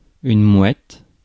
Ääntäminen
Synonyymit mauve Ääntäminen France: IPA: [mwɛt] Haettu sana löytyi näillä lähdekielillä: ranska Käännös Substantiivit 1. чайка {f} (čájka) Suku: f .